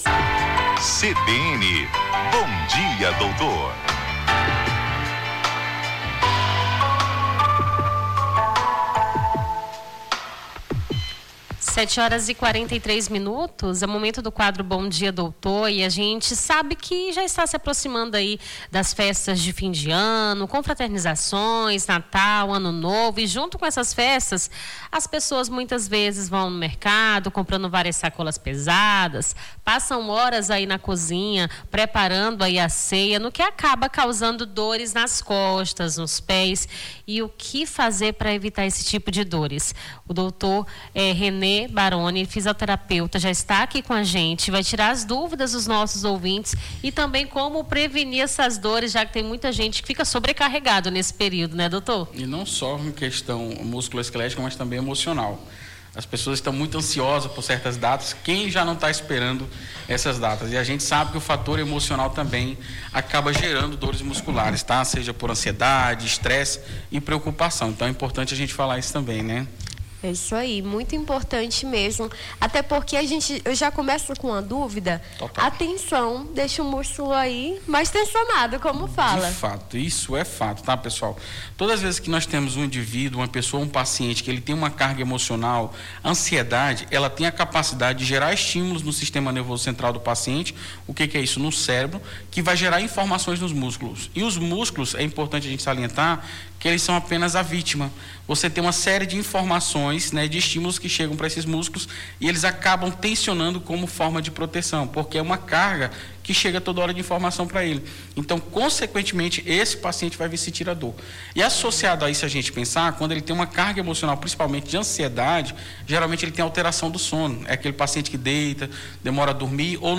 Nesta quinta-feira, 05, recebemos no stúdio da CBN Amazônia